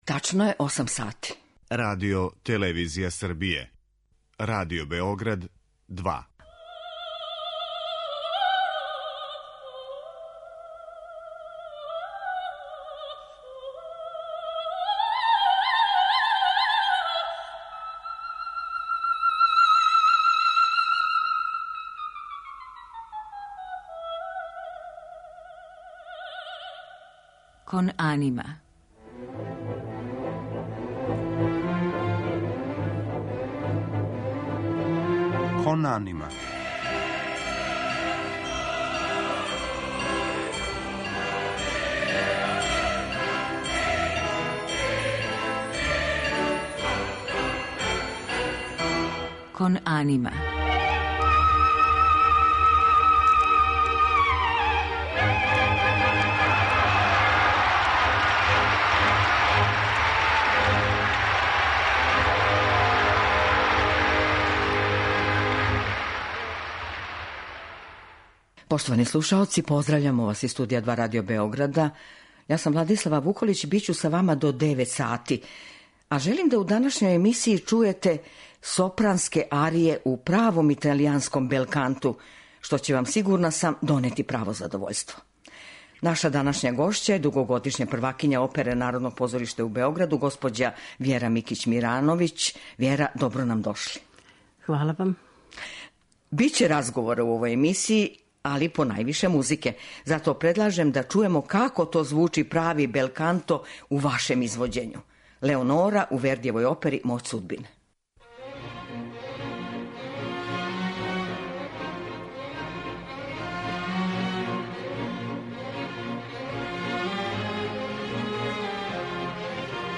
Разговор са њом откриће приступ који ова уметница има према одређеним улогама, као и профилима појединих ликова у њеном тумачењу који су остали запамћени као врхунска остварења.